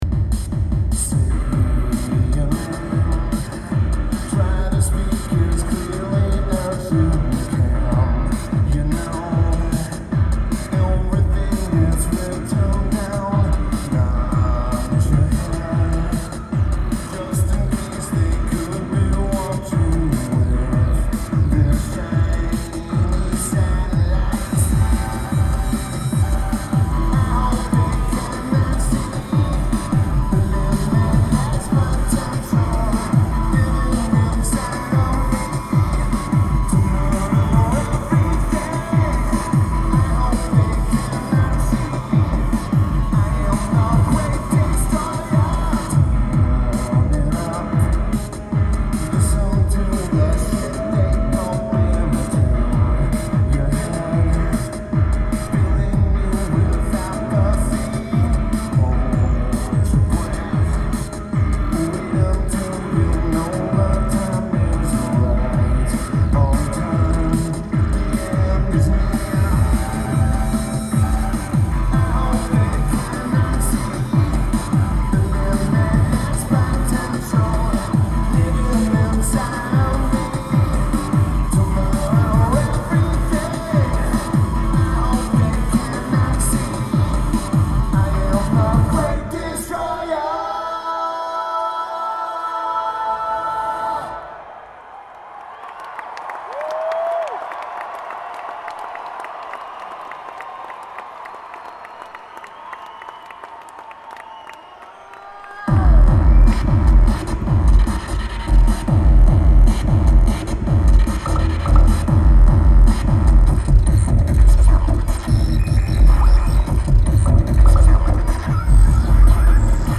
White River Amphitheater